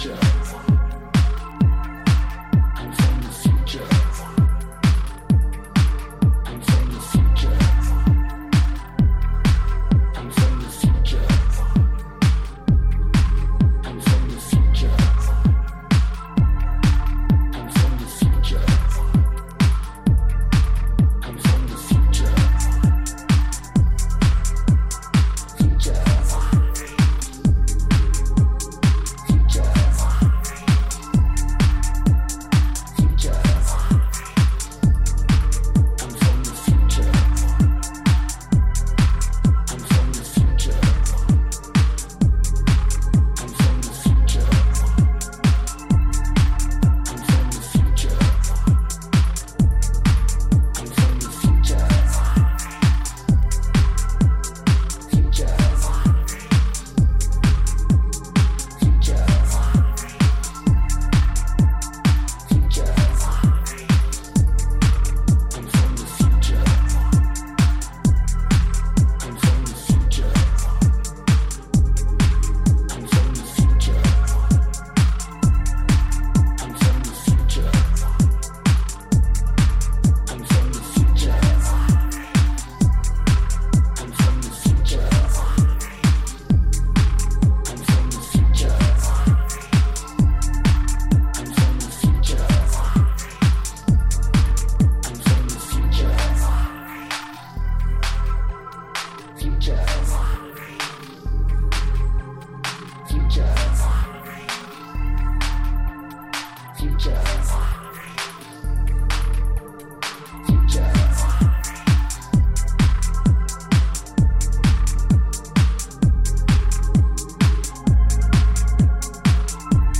supplier of essential dance music
Focused and deep club-ready workouts